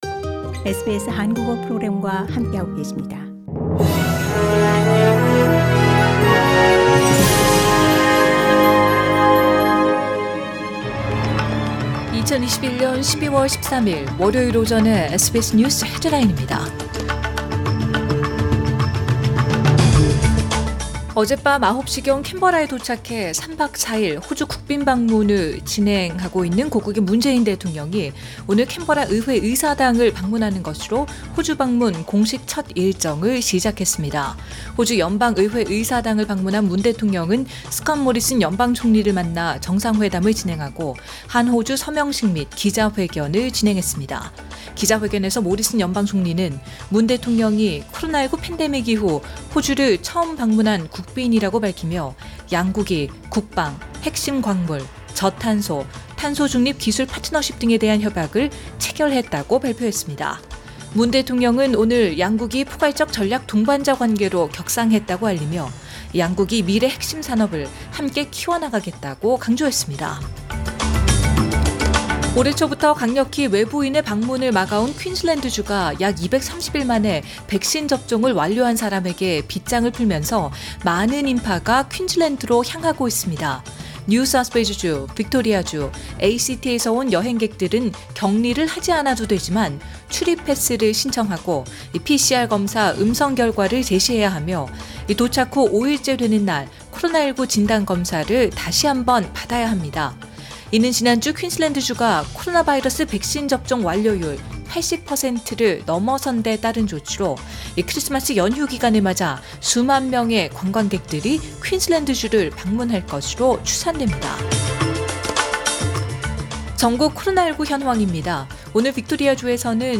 2021년 12월 13일 월요일 오전의 SBS 뉴스 헤드라인입니다.